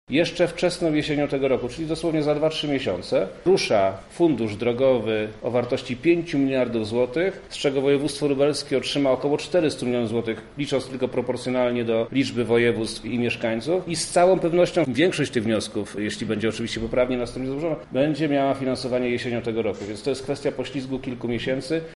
– mówi Wojewoda Lubelski Przemysław Czarnek.